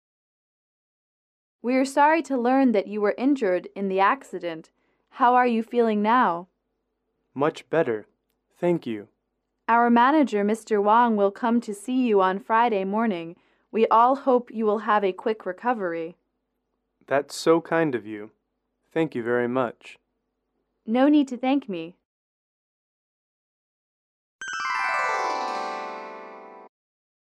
英语口语情景短对话20-3：看望病人（MP3)